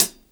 hat 4.wav